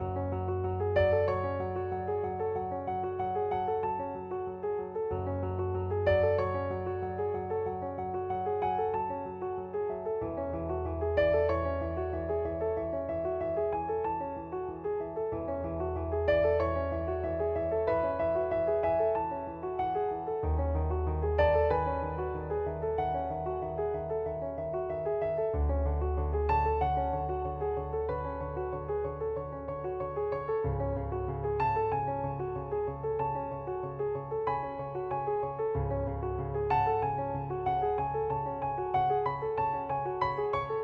描述：在RASD（阿拉伯撒哈拉民主共和国）难民营等待人道主义援助分配的妇女"27 de Febrero"。来自远处的单声道录音
Tag: 喋喋不休 F emale 外观 等待 阿拉伯语 会议 撒哈拉 妇女 hassania 记录 沃拉 阿拉伯